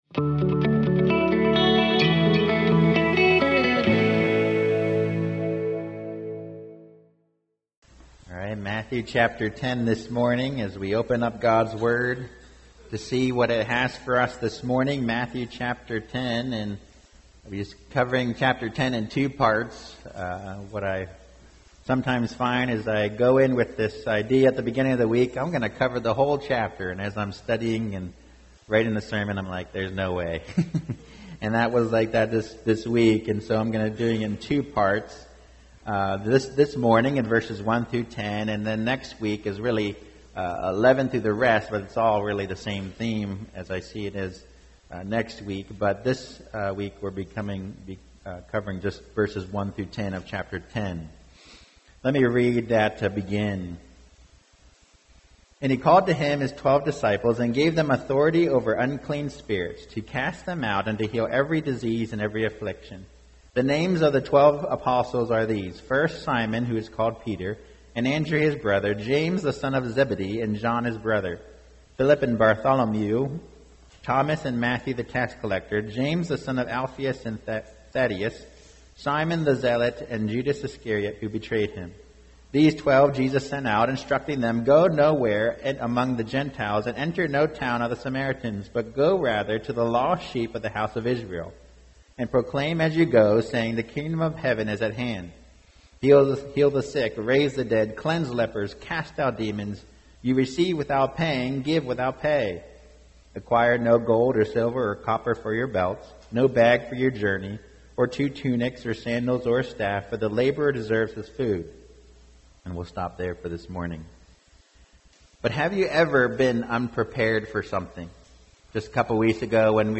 Part 1 Preacher